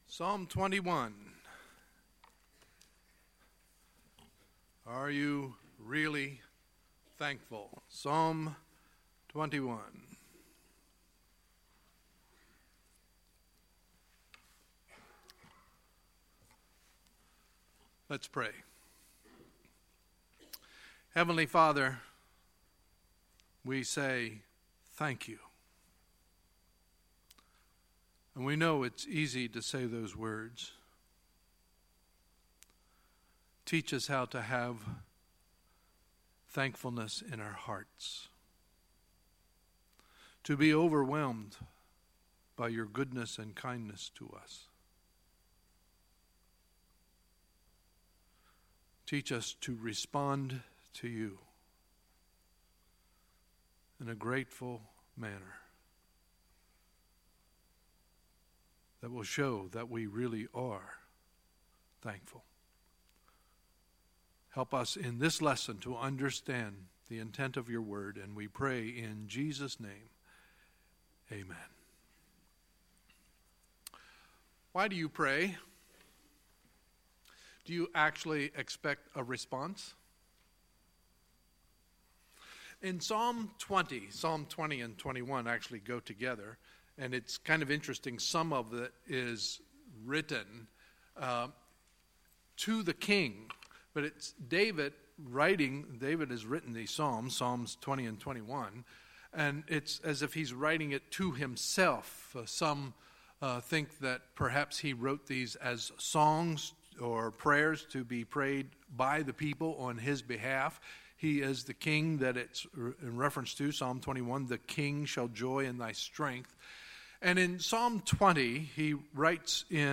Sunday, November 19, 2017 – Sunday Morning Service